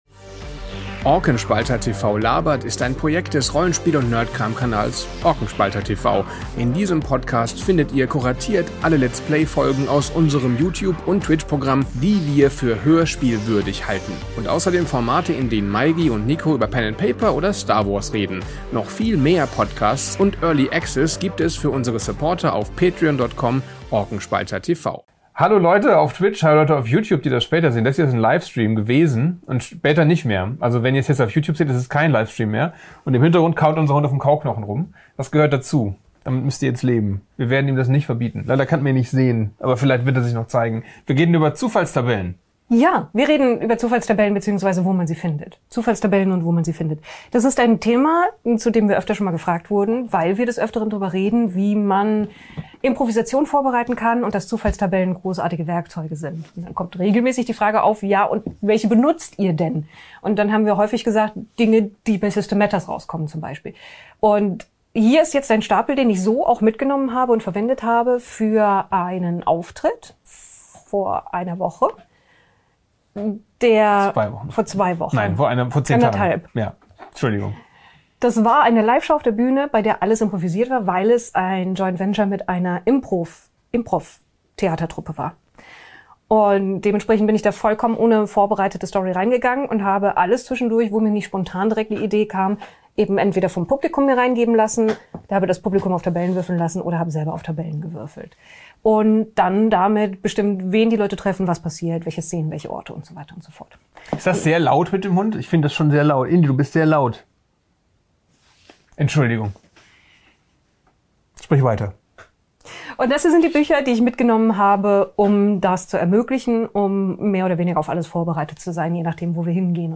Das war ein Video auf Twitch, daher sorry, dass hier die Bildkomponente fehlt.